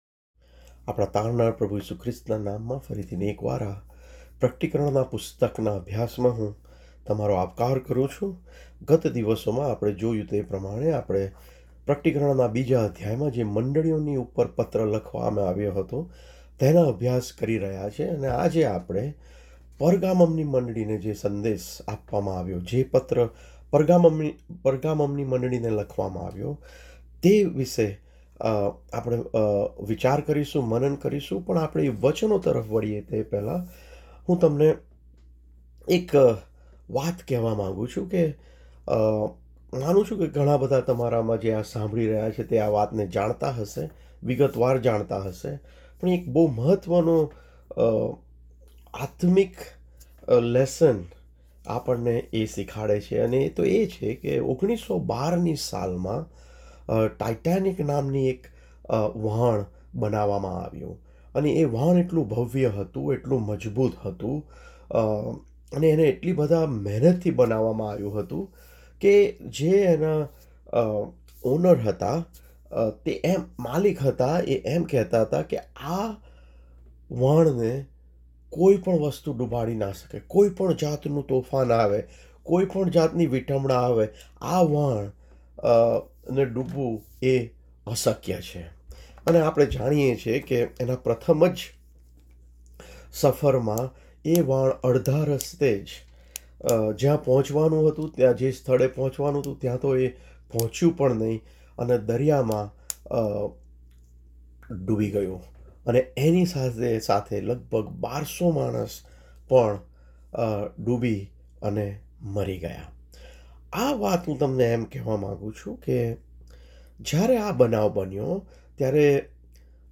Thanks for the Bible study.